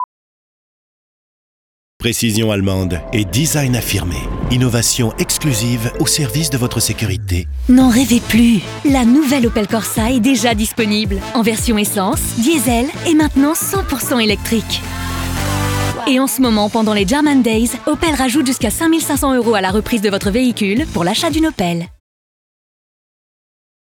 Voix Off Pub TV Opel Corsa